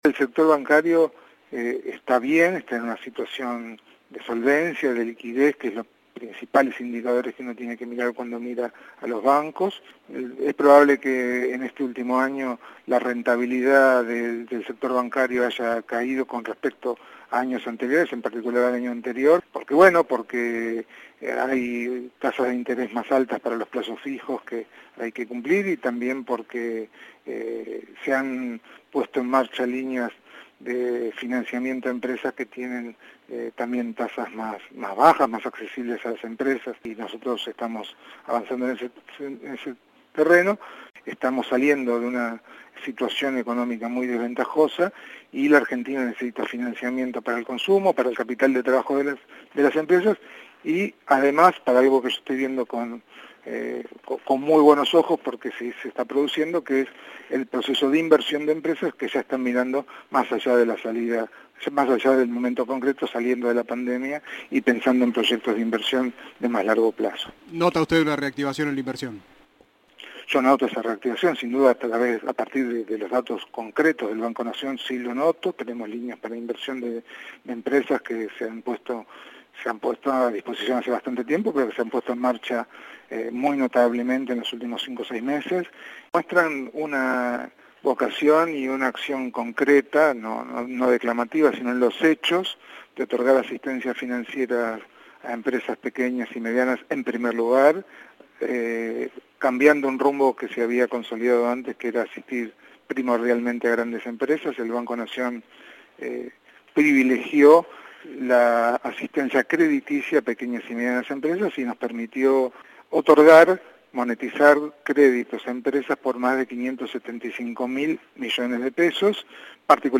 El presidente del Banco Nación, Eduardo Hecker, indicó a Cadena 3 que priorizan la asistencia financiera para las Pymes y también a familias para el consumo.